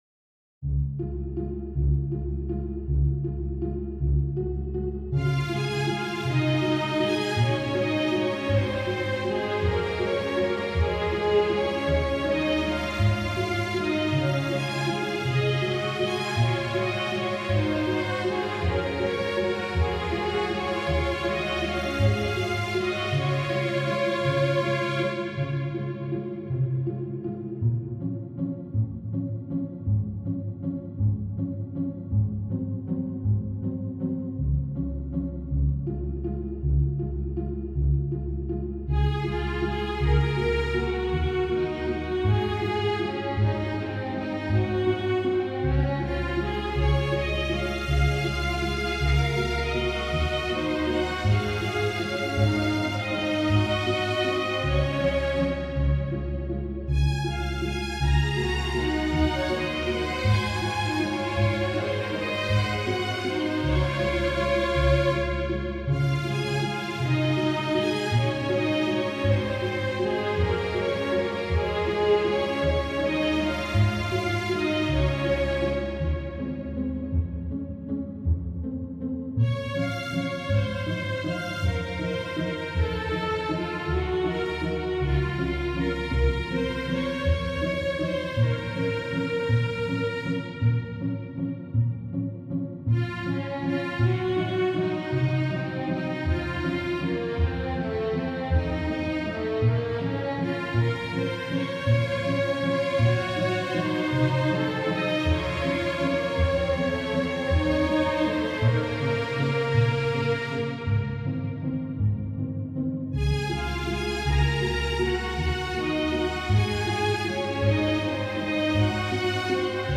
A waltz